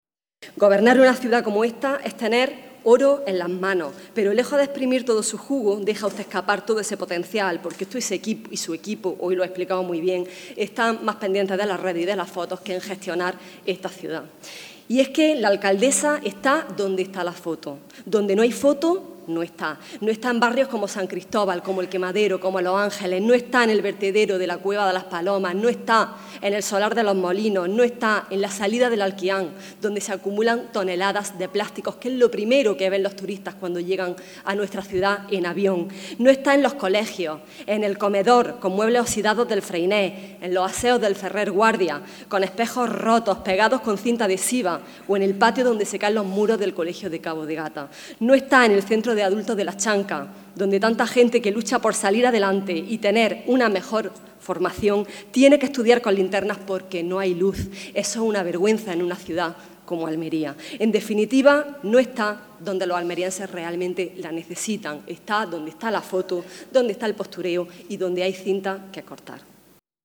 Son palabras de la portavoz del PSOE, Fátima Herrera, durante el debate sobre el estado de la ciudad que se ha celebrado esta mañana.